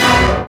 SYN DANCE0CL.wav